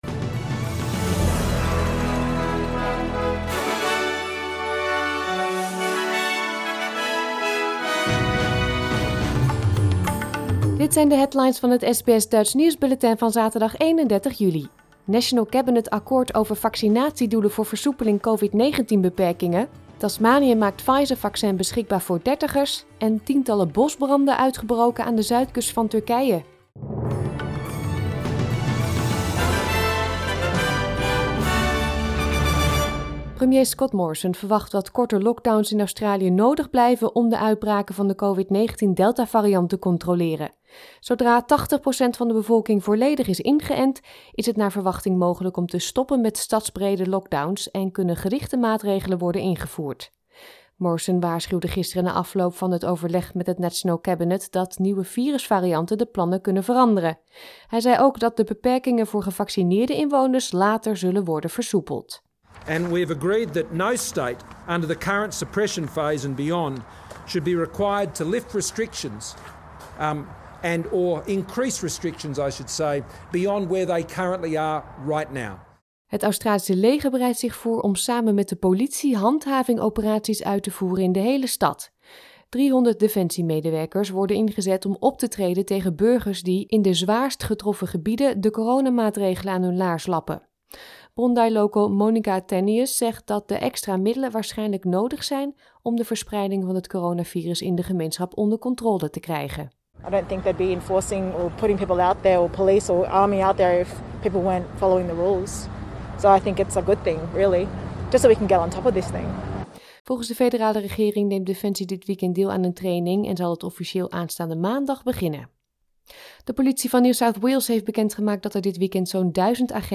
Nederlands/Australisch SBS Dutch nieuwsbulletin van zaterdag 31 juli 2021